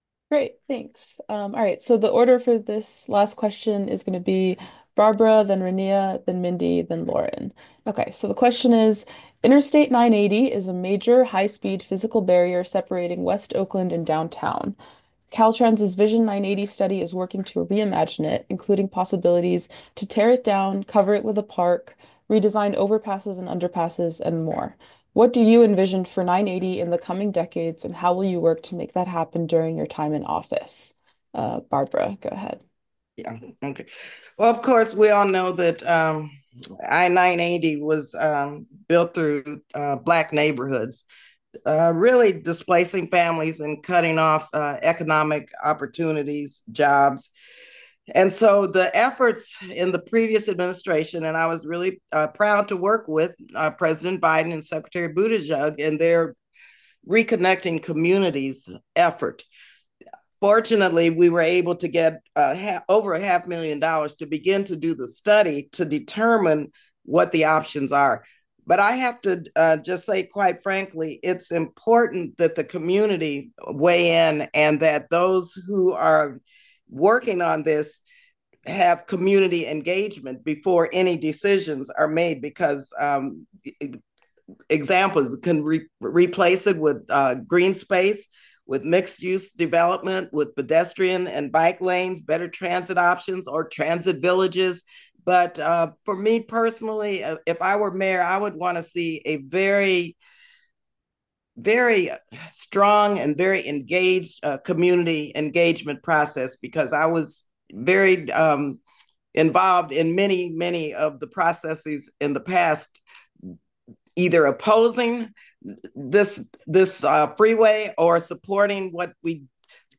Four of the candidates vying to replace ousted Oakland Mayor Sheng Thao joined a transportation forum Tuesday evening to discuss their views for the future of the city.